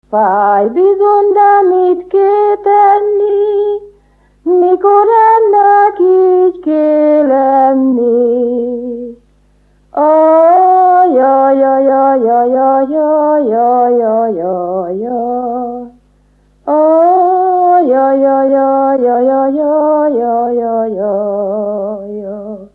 Erdély - Udvarhely vm. - Korond
Műfaj: Keserves
Stílus: 3. Pszalmodizáló stílusú dallamok
Kadencia: 8 (4) b3 1